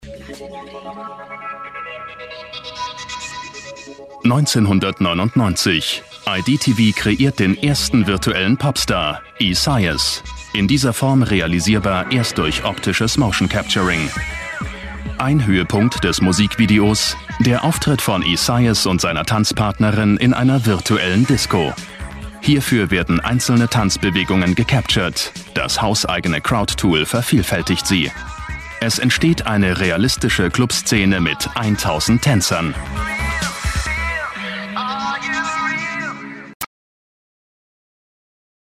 deutscher Sprecher
Kein Dialekt
Sprechprobe: Werbung (Muttersprache):
german voice over artist